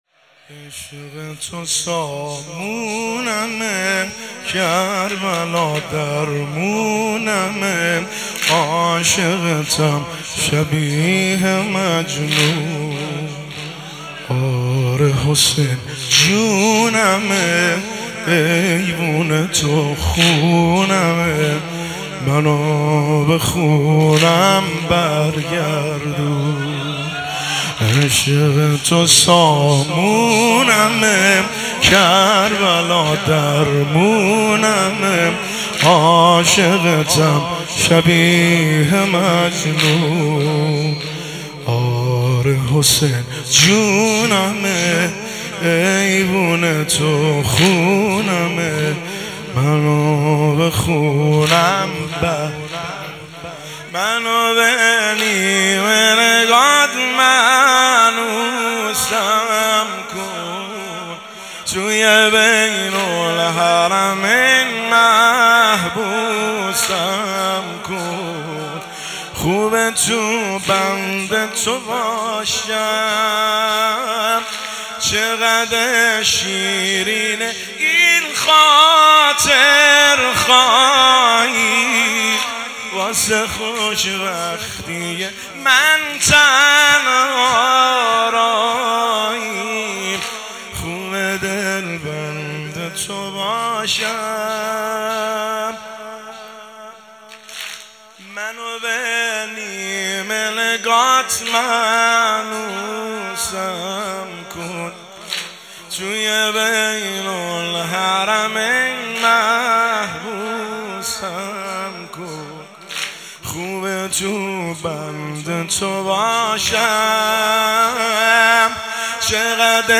سنگین – شام وفات حضرت اُم البنین (س) 1403